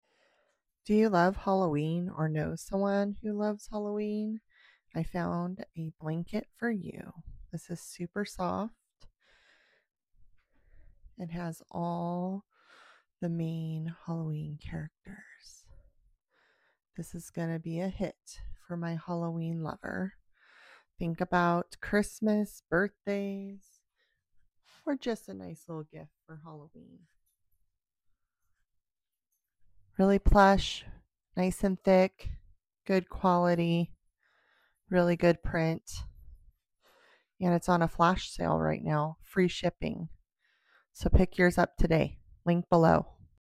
Soft throw blanket Horror Halloween sound effects free download